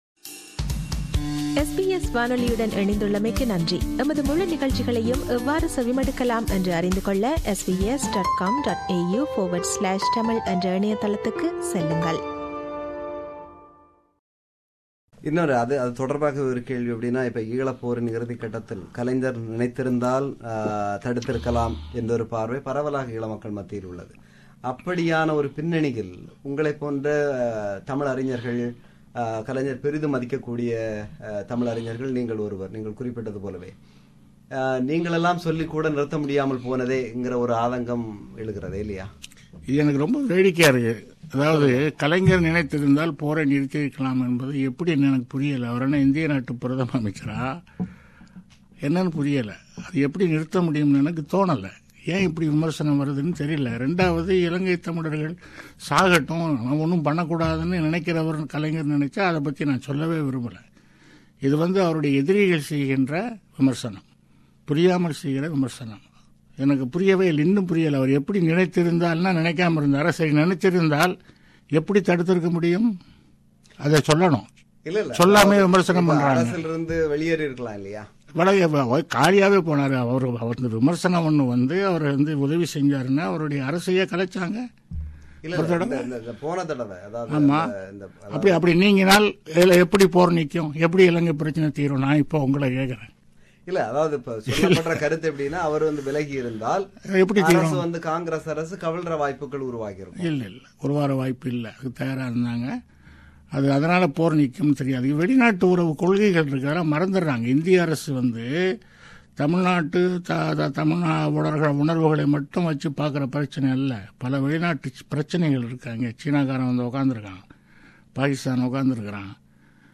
Interview with ‘Kavikko’ Abdul Rahman – Part 3
Its a re-broadcast of the interview.